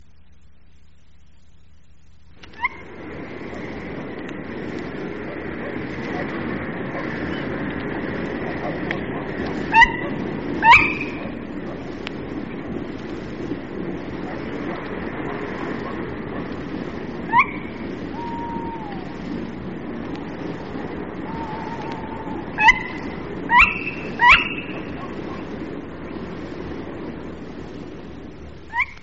Kostenlose Klingeltöne Waldkauz